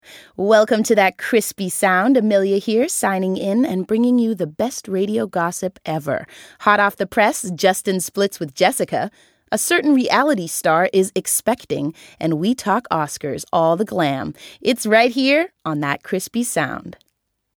» Stemmeprøver